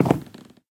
Minecraft / step / wood5.ogg
wood5.ogg